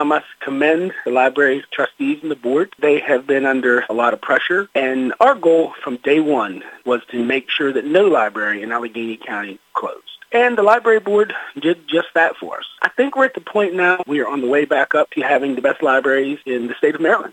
Coburn commended the ACLS board of trustees, saying they worked through the pressure they have been under recently…